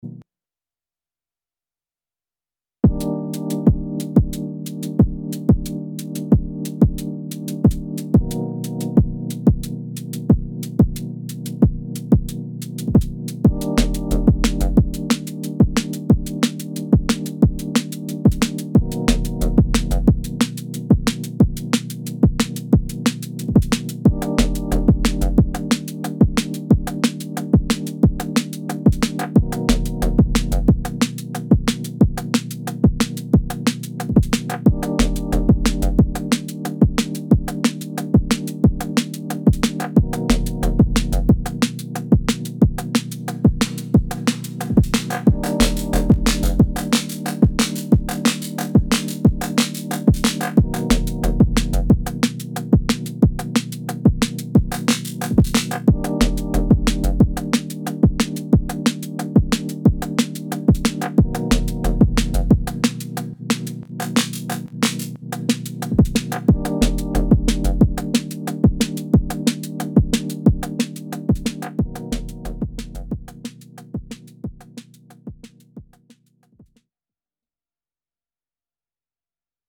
8 x SY CHORD, a simple dnb pattern. my focus this time was on building a snare drum using three different channels / tracks (track 2: transient, track 3: body, track 4: tail/rattle).
Cool bass as well, I can hear it develop a little wobble in a longer track :slight_smile: